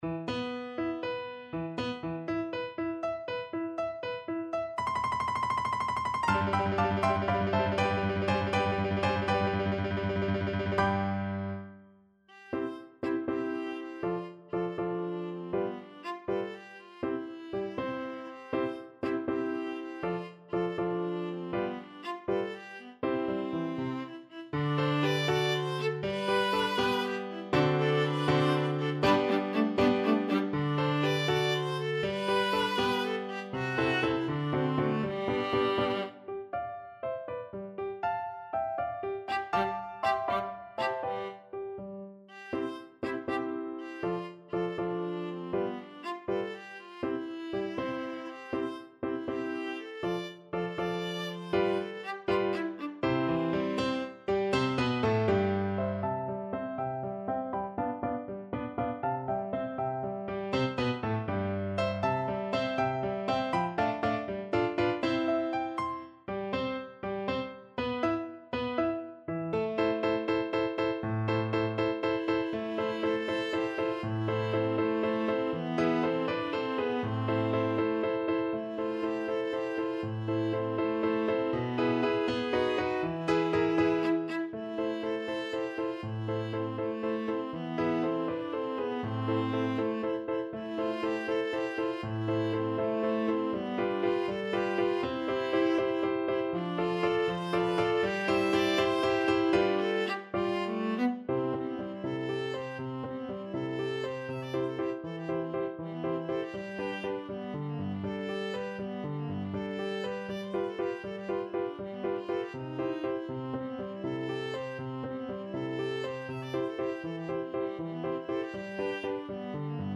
Viola
6/8 (View more 6/8 Music)
C major (Sounding Pitch) (View more C major Music for Viola )
~ = 100 Allegretto moderato .=80
C4-F6
Classical (View more Classical Viola Music)